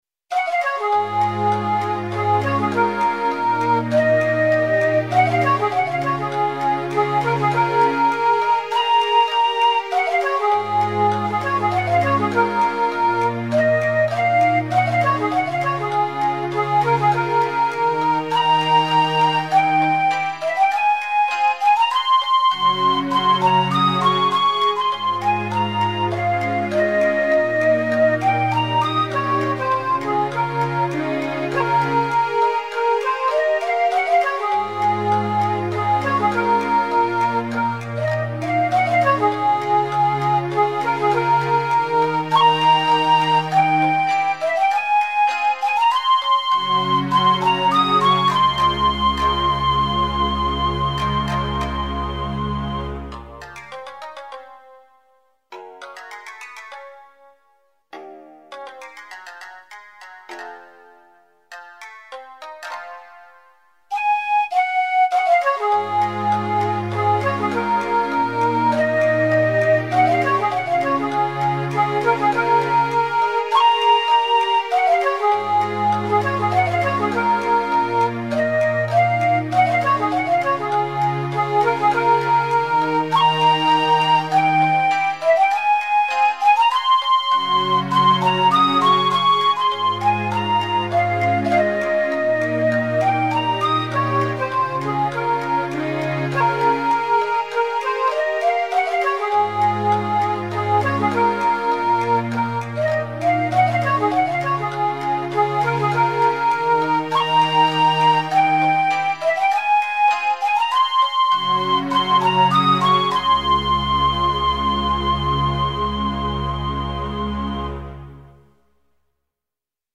Japanese Music Style
穏やかに流れる春の日のイメージ。尺八メイン、サブに三味線による和風アレンジ。